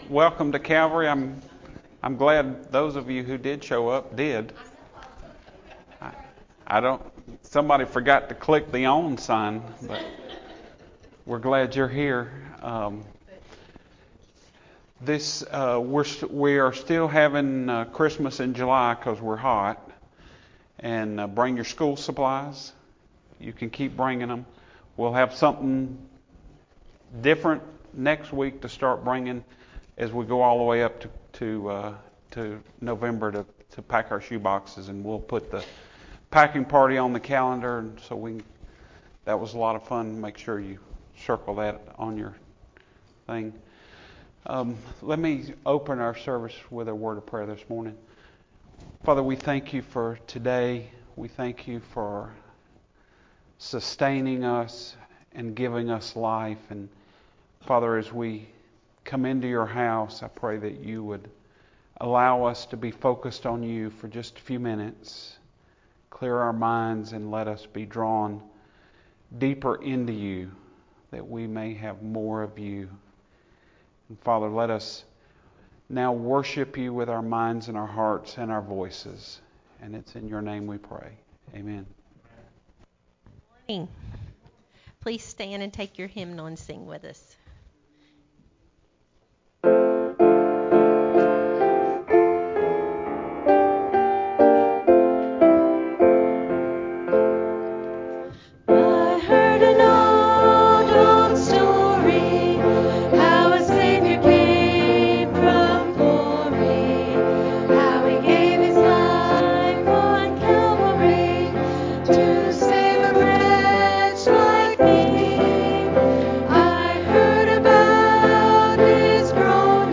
Links to videos used in today’s sermon.